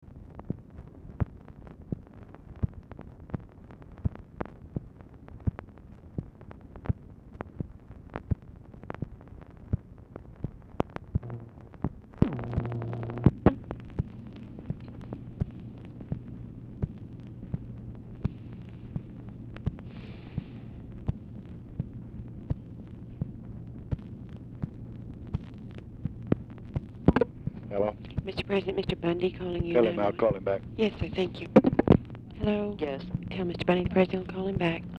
Telephone conversation # 9486, sound recording, LBJ and OFFICE SECRETARY, 1/11/1966, time unknown | Discover LBJ
OFFICE SECRETARY ON HOLD 0:27
Format Dictation belt
Specific Item Type Telephone conversation